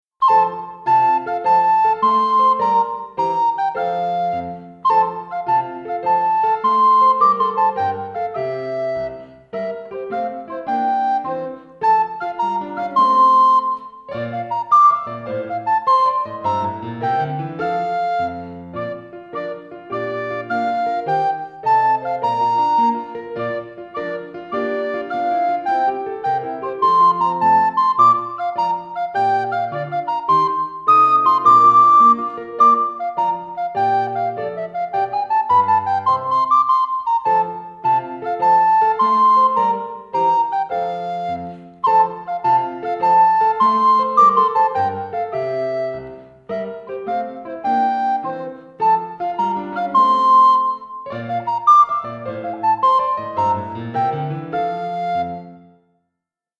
CHILDREN'S MUSIC ; SILLY MUSIC